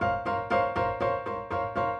Index of /musicradar/gangster-sting-samples/120bpm Loops
GS_Piano_120-C2.wav